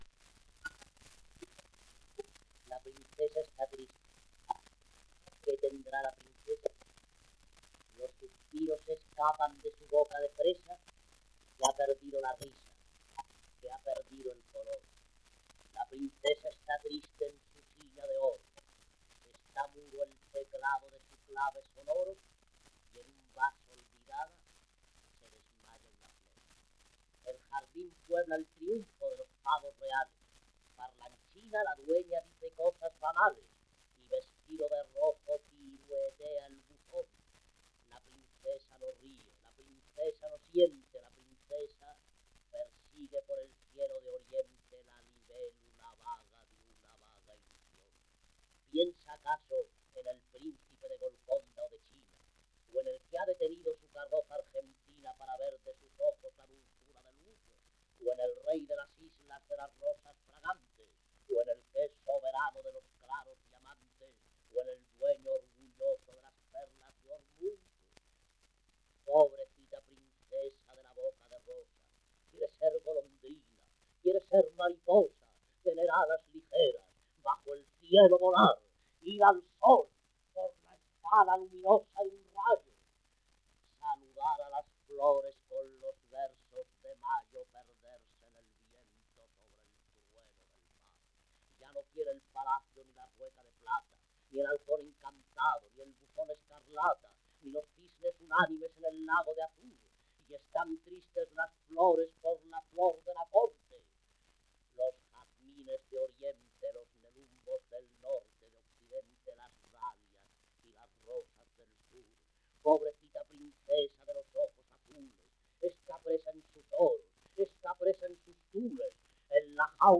Sonatina (sonido remasterizado)
1 disco : 78 rpm ; 25 cm Intérprete Ricardo Calvo, recitando